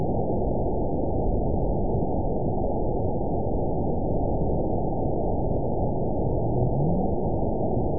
event 912491 date 03/27/22 time 20:33:11 GMT (3 years, 1 month ago) score 9.57 location TSS-AB04 detected by nrw target species NRW annotations +NRW Spectrogram: Frequency (kHz) vs. Time (s) audio not available .wav